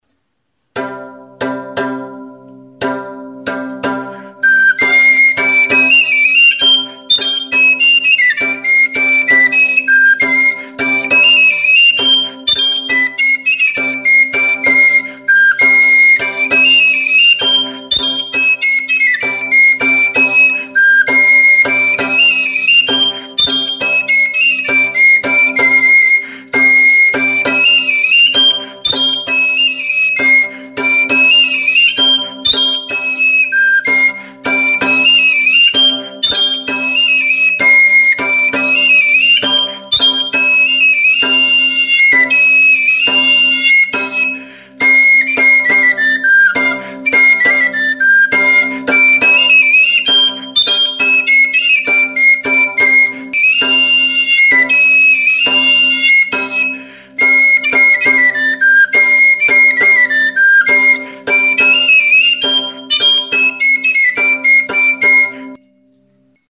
Aerophones -> Flutes -> Fipple flutes (one-handed)
Recorded with this music instrument.
Hiru zuloko flauta zuzena da.